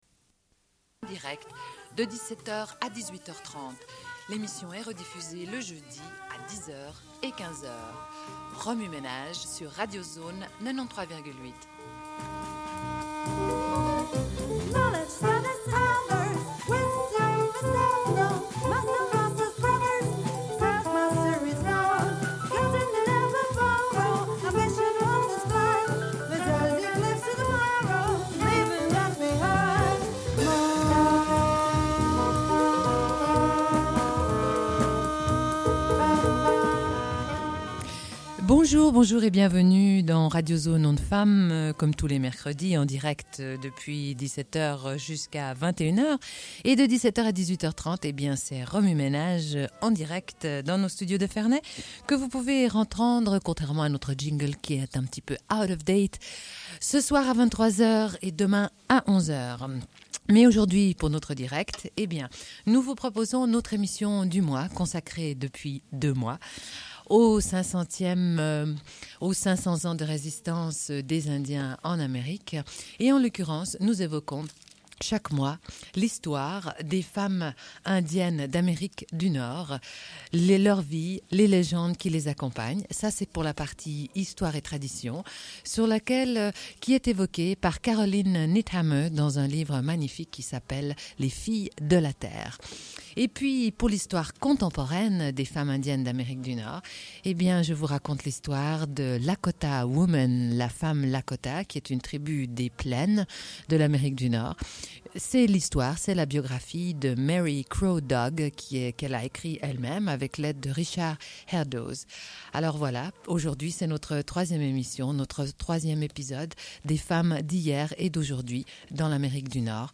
Une cassette audio, face A00:30:48